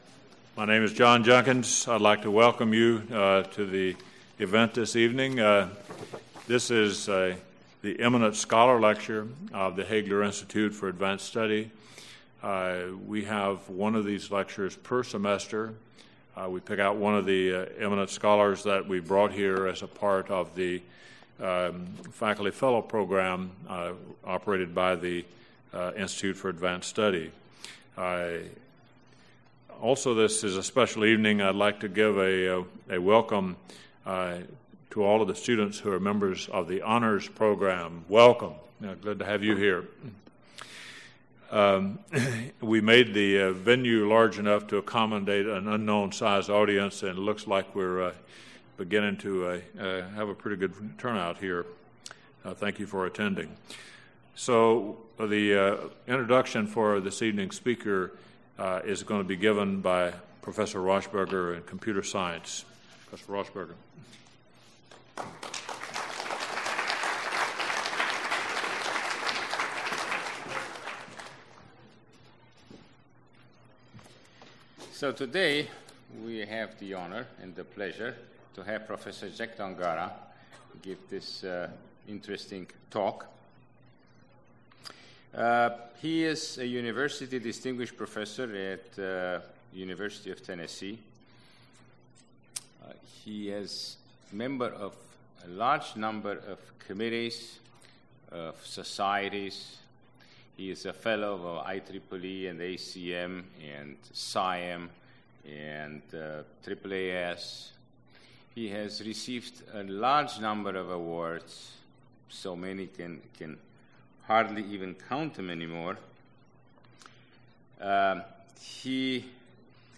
Eminent Scholar Lecture